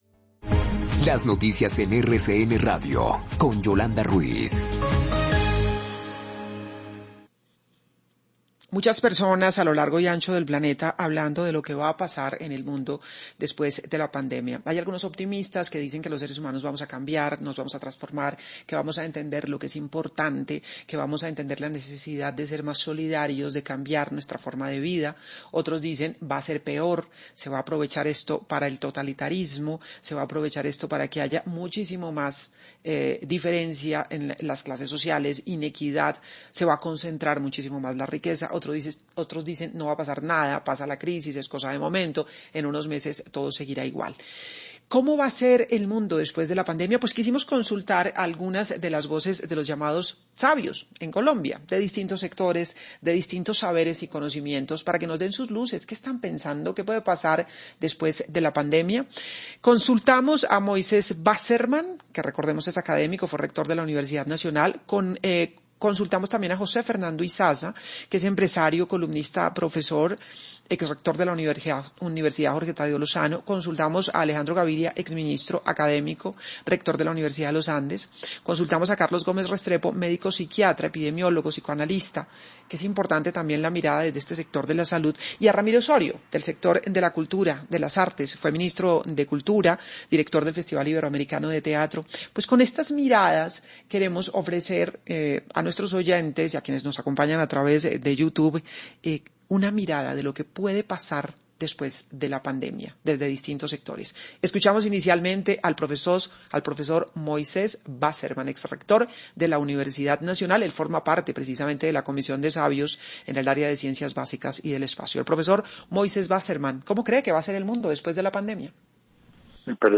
¿Cómo será el mundo luego de que pase la pandemia? Consultados por RCN Radio, algunos destacados pensadores y analistas del país dan luces de lo que nos espera cuando la pandemia desaparezca.